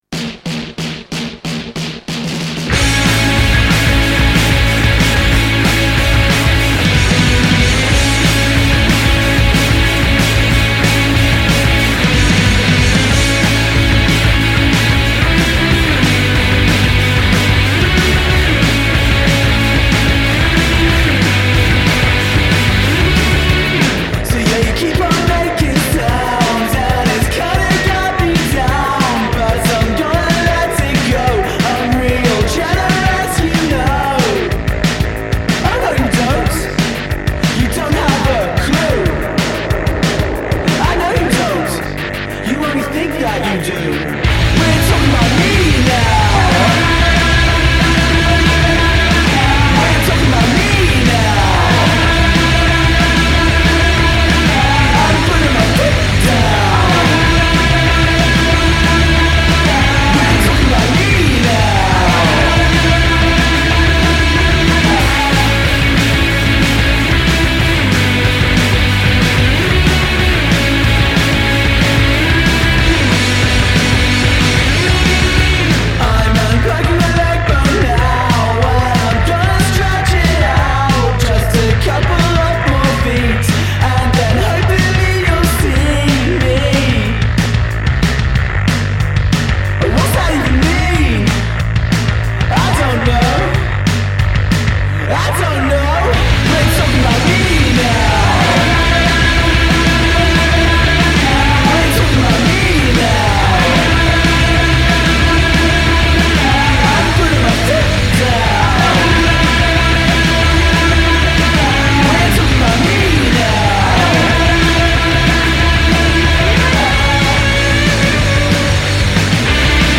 garage-surf trio
never knowingly subtle